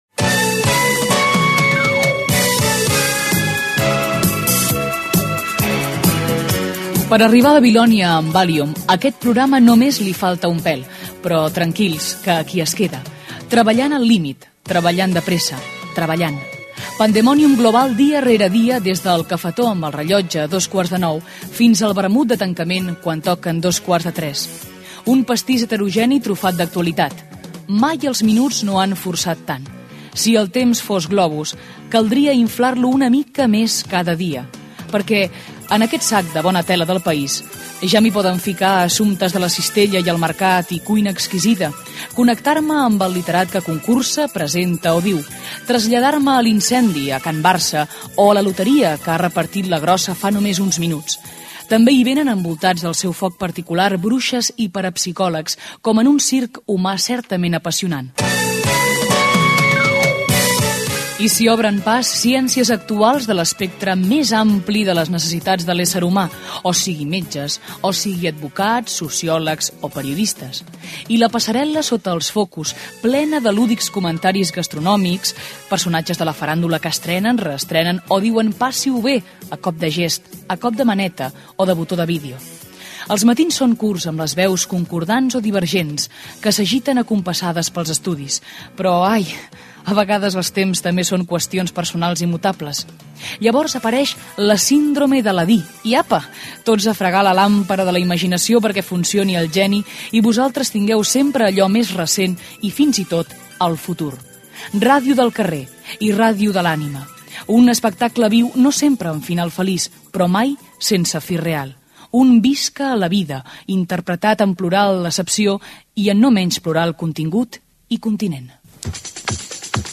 Viva la gente: entrevista Jordi Pujol - Antena 3 Ràdio, 1988